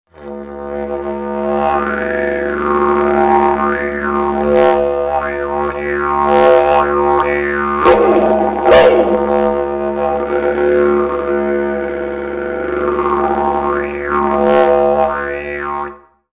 didgeridoo - bamboo
didgeridoo-bamboo.mp3